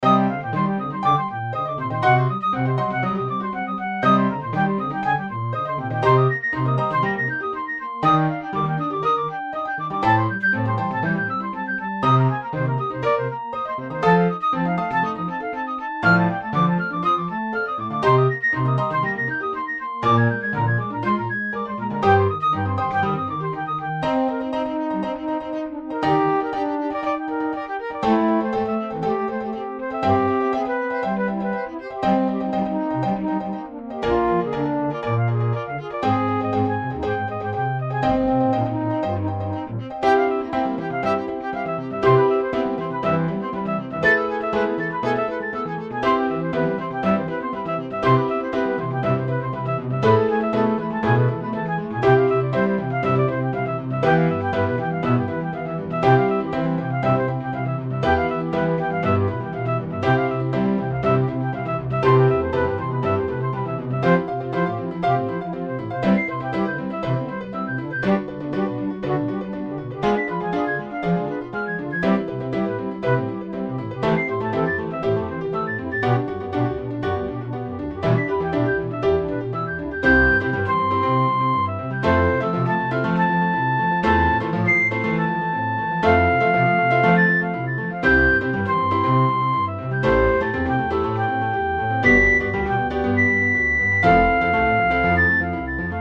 ピッコロ、ピアノ、コントラバス
BGM